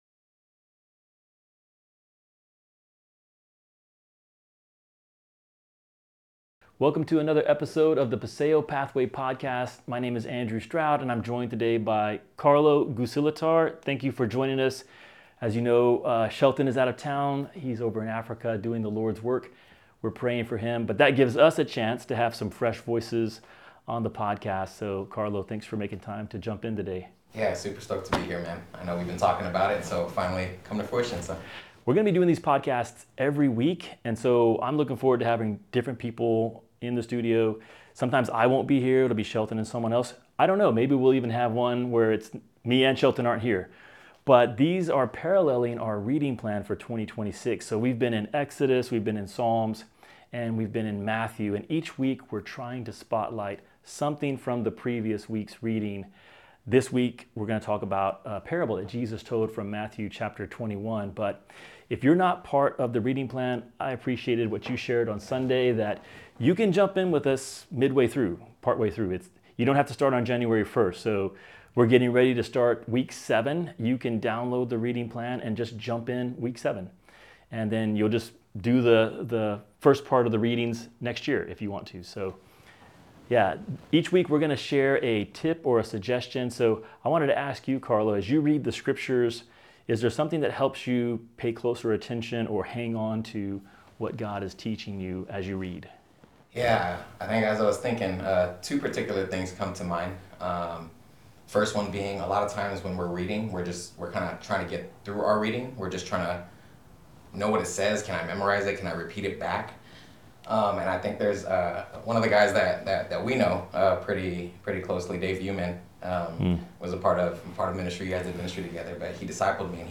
Note: We had an issue with the audio on this episode but hope you still receive a lot of encouragement from it.